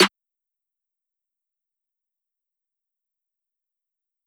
Snare (Since Way Back).wav